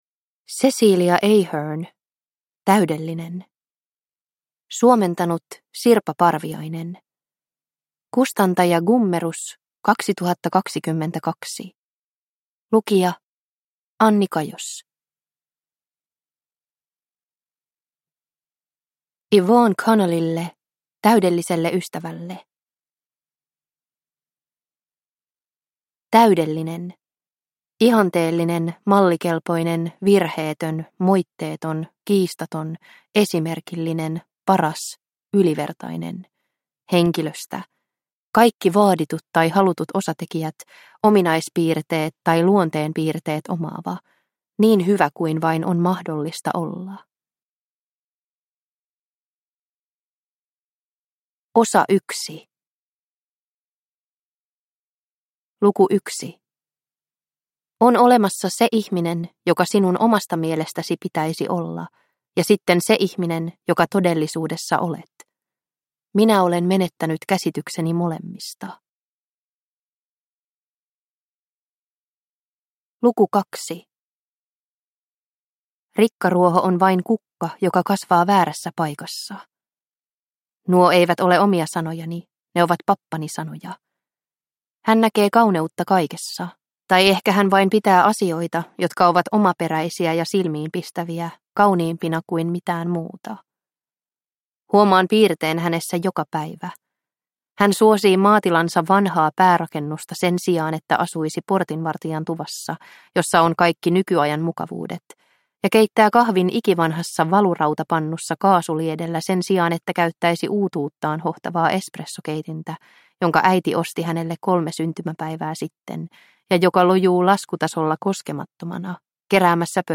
Täydellinen – Ljudbok – Laddas ner